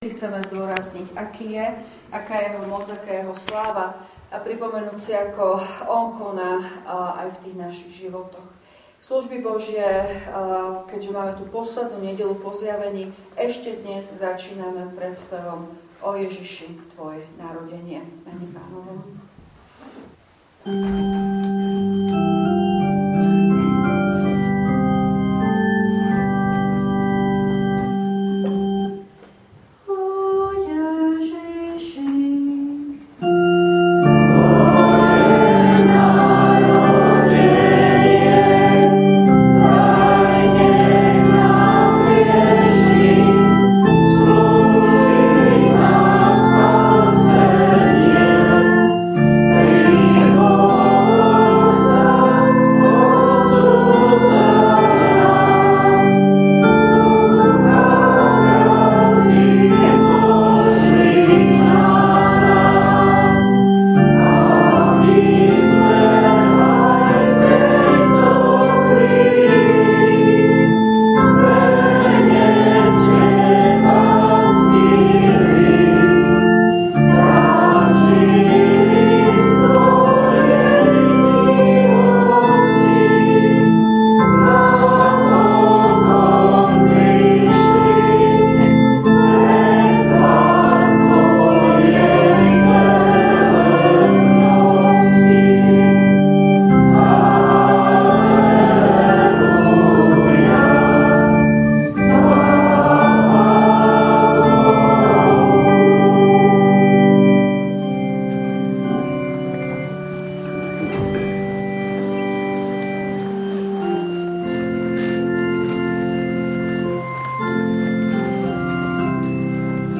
V nasledovnom článku si môžete vypočuť zvukový záznam zo služieb Božích – 5. nedeľa po Zjavení.